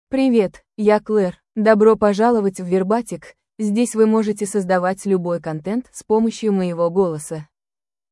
Claire — Female Russian AI voice
Voice sample
Listen to Claire's female Russian voice.
Female
Claire delivers clear pronunciation with authentic Russia Russian intonation, making your content sound professionally produced.